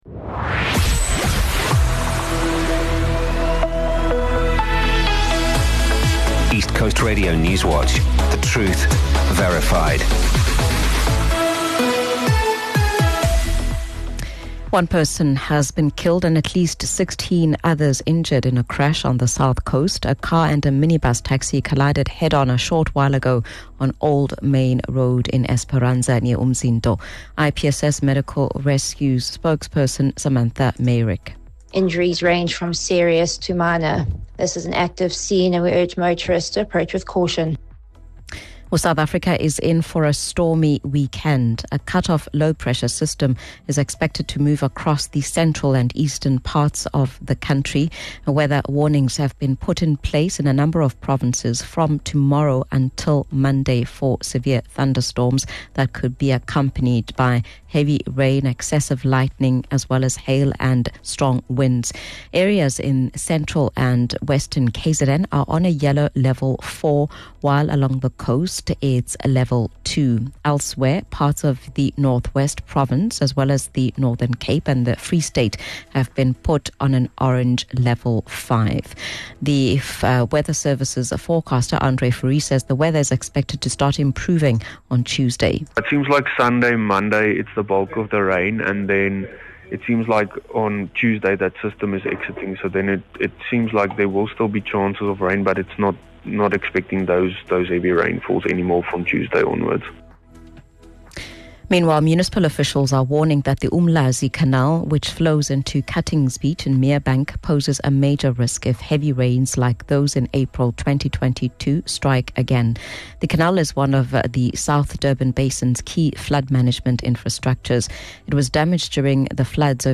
East Coast Radio Newswatch is the independent Durban-based radio station's news team. We are KwaZulu-Natal’s trusted news source with a focus on local, breaking news. Our bulletins run from 6am until 6pm, Monday to Friday.